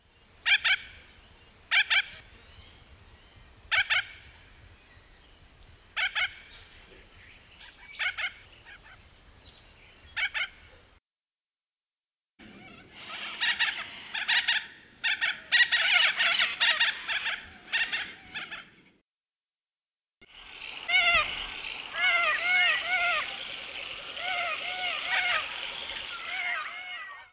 The calls of the two species are also, to the untrained ear, indistinguishable.
Click on the audio bar below to hear calls of what we think were Long-billed Corellas.
long-billed-corella.mp3